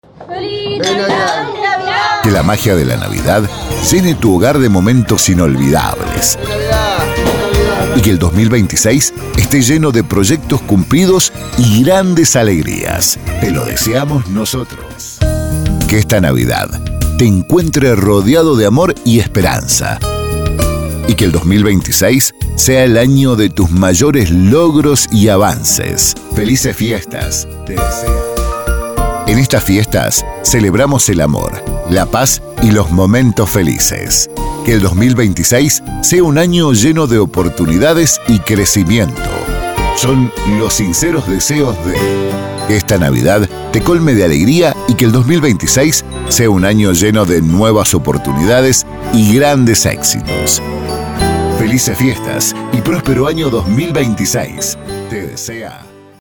✅ Fondos musicales y FX de máxima calidad.